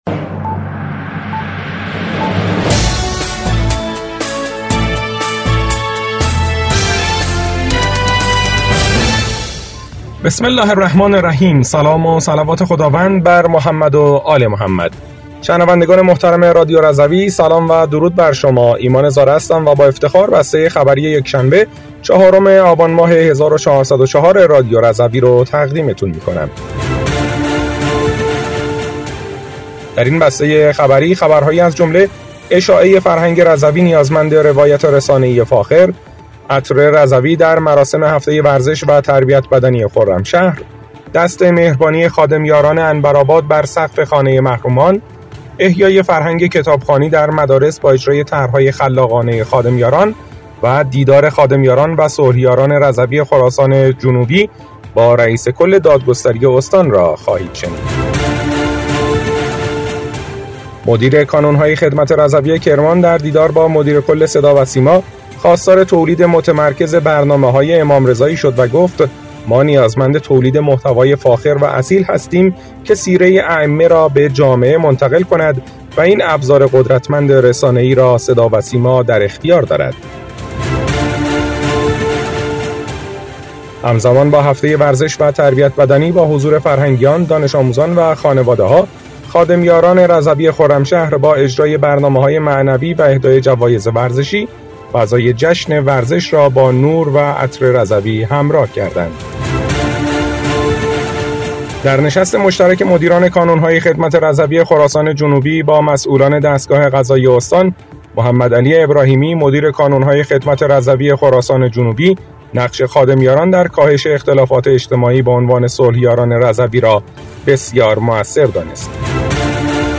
بسته خبری ۴ آبان ۱۴۰۴ رادیو رضوی؛